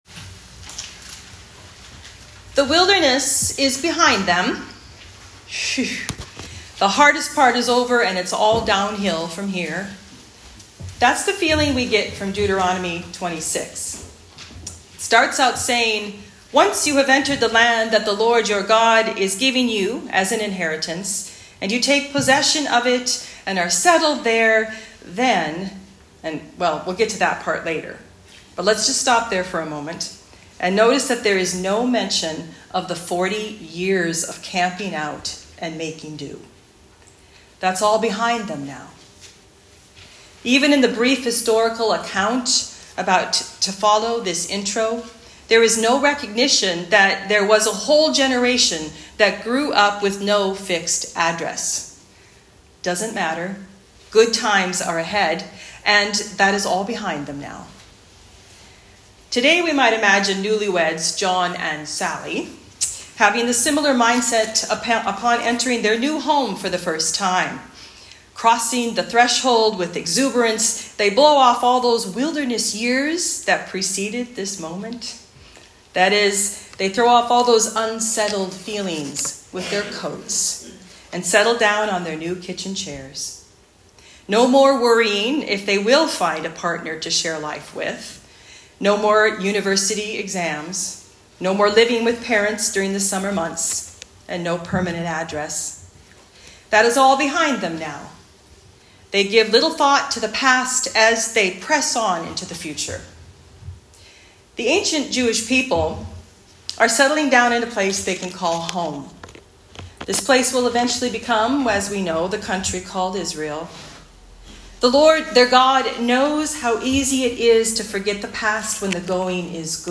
Sermon for Lent 1